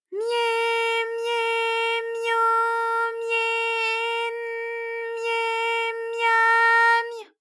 ALYS-DB-001-JPN - First Japanese UTAU vocal library of ALYS.
mye_mye_myo_mye_n_mye_mya_my.wav